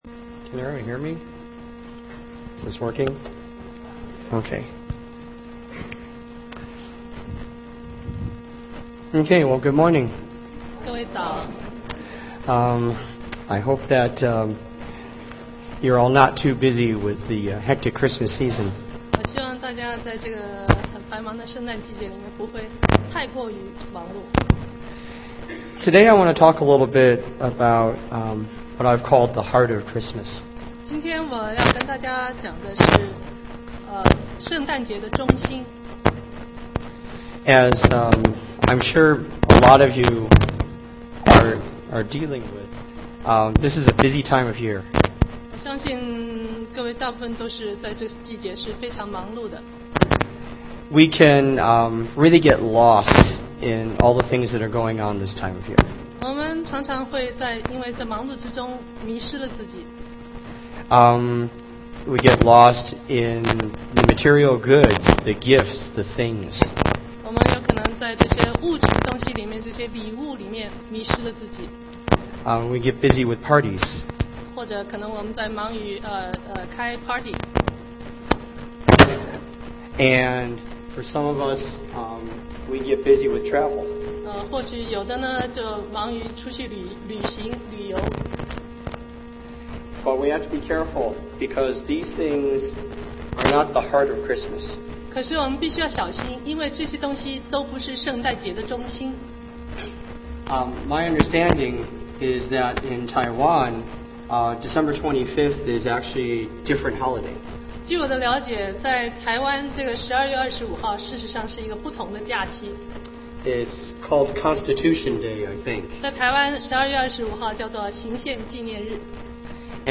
Sermon 2010-12-19 The Heart of Christmas